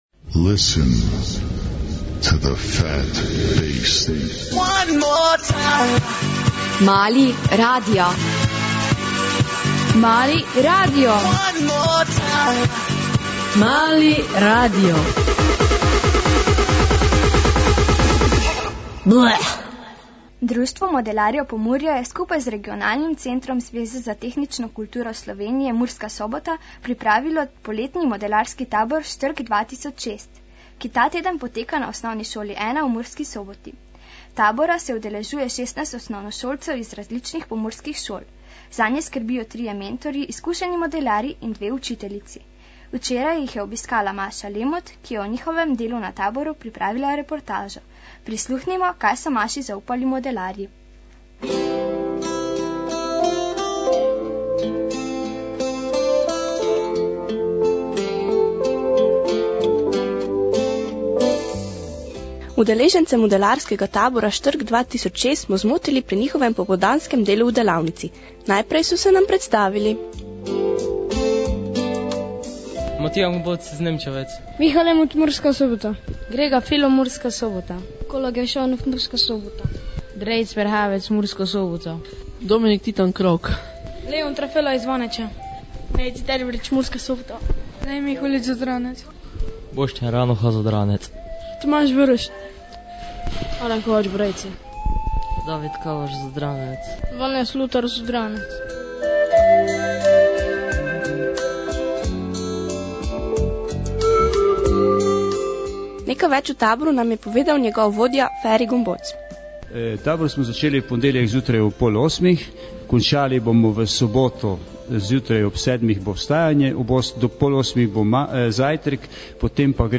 Reporta�a v oddaji Mali radio na Murskem valu